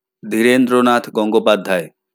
Audioaufzeichnung der Aussprache eines Begriffs.